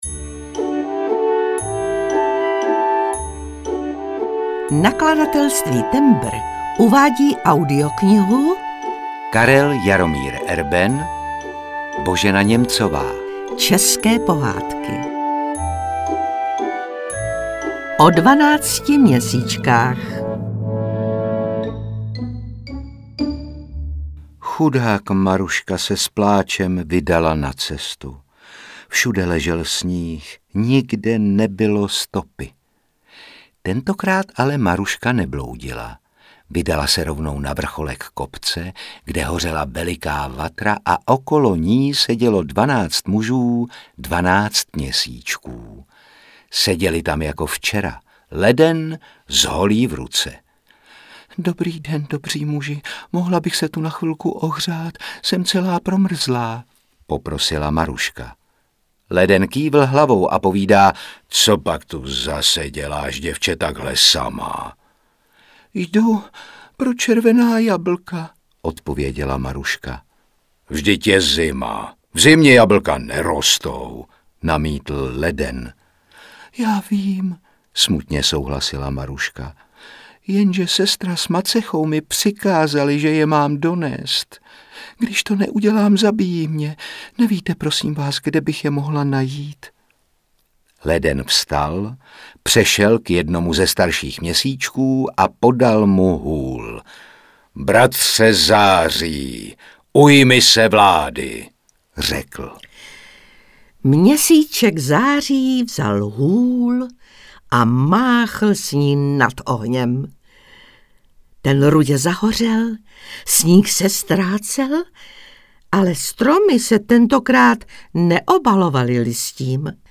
České pohádky audiokniha
Ukázka z knihy
• InterpretRůžena Merunková, Otakar Brousek ml.